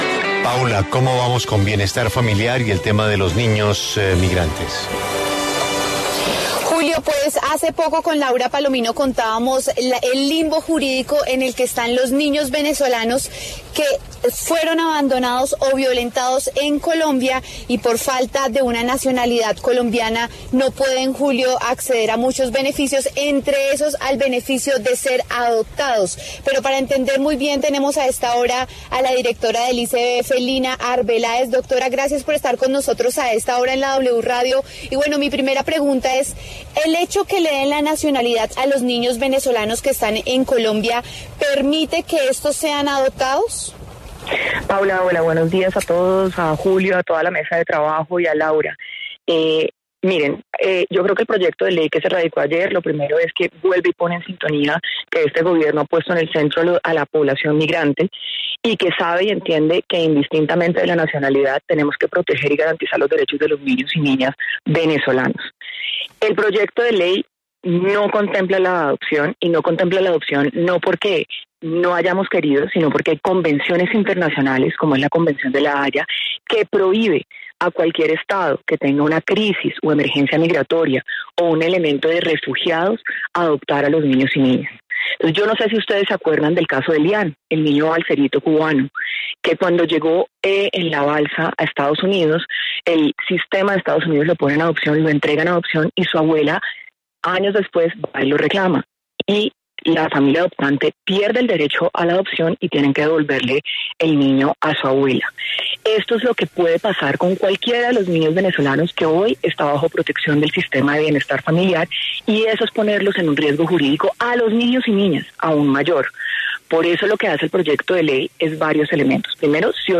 En diálogo con La W, Lina Arbeláez, directora del ICBF, se refirió al proyecto que busca dar nacionalidad a los menores de edad migrantes que están bajo custodia del instituto.